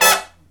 HIGH HIT16-L.wav